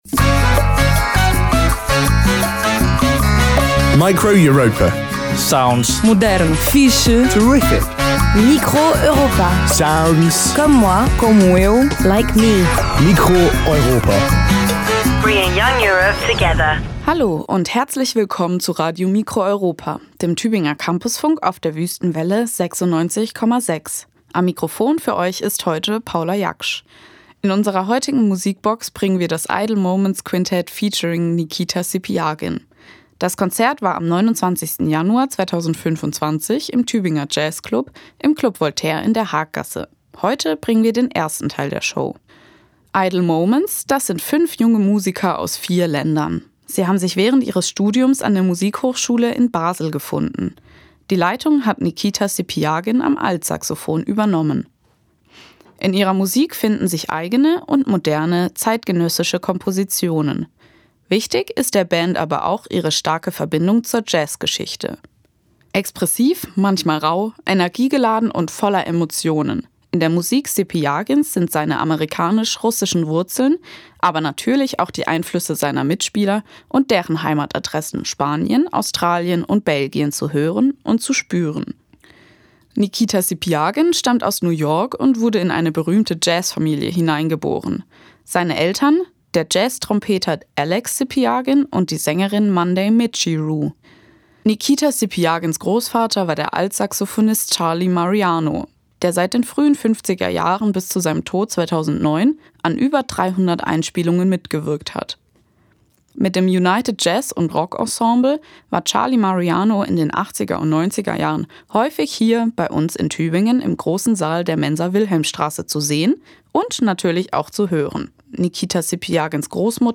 Altsaxofon
Form: Live-Aufzeichnung, geschnitten